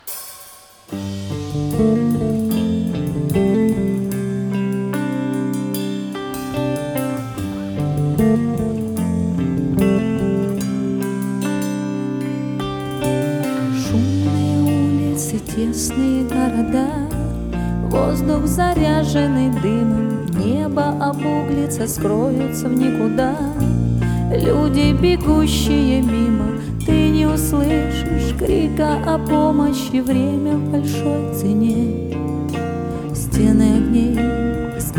Жанр: Русская поп-музыка / Поп / Рок / Русский рок / Русские